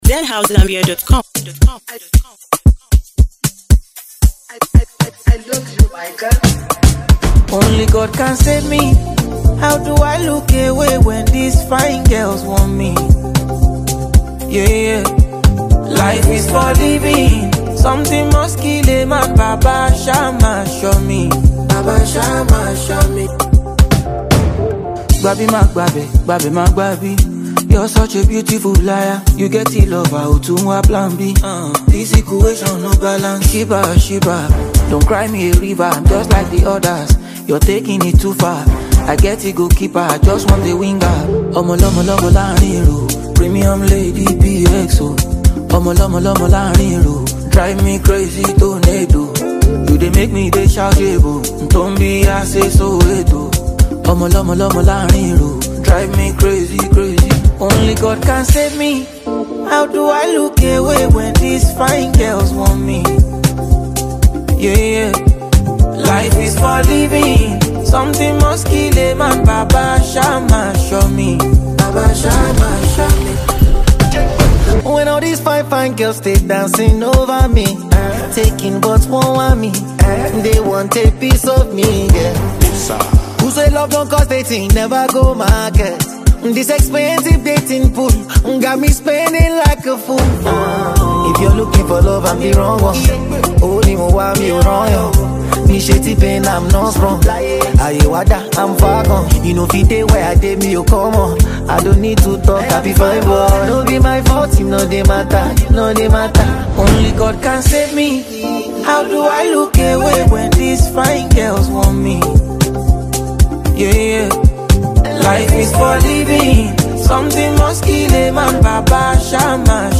blends soulful melodies with heartfelt lyrics